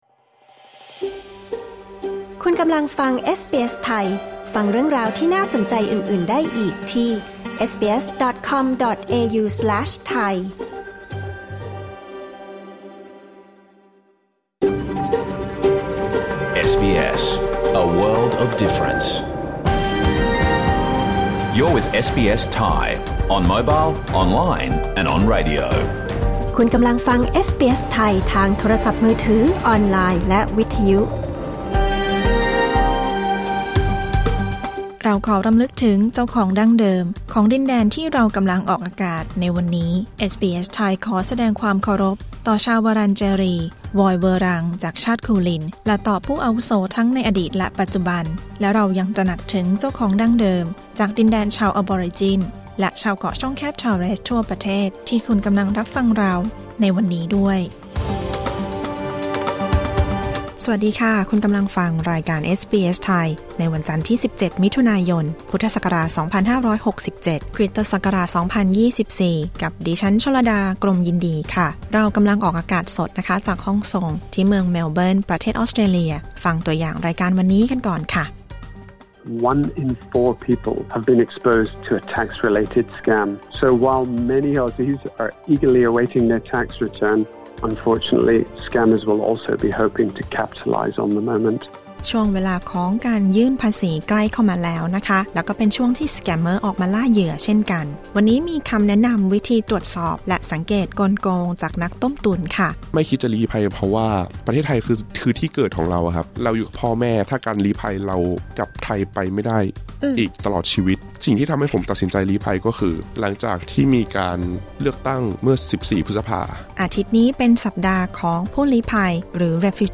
รายการสด 17 มิถุนายน 2567